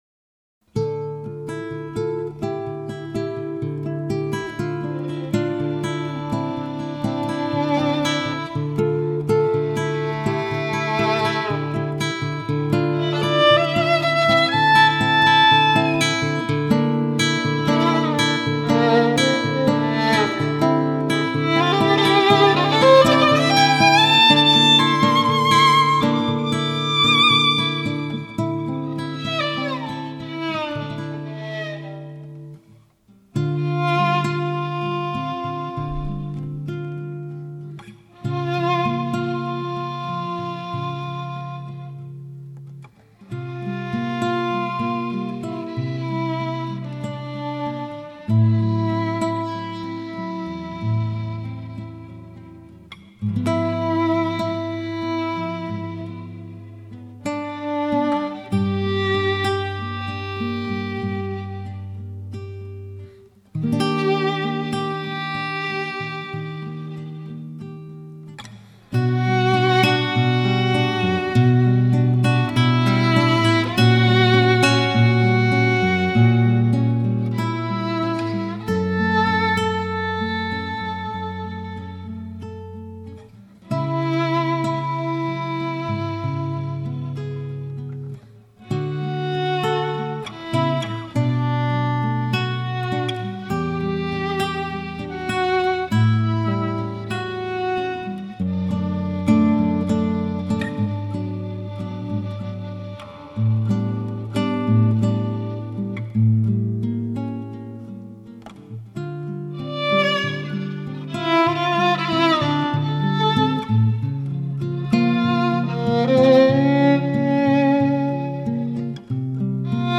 chitarra
batteria
basso
sax
violino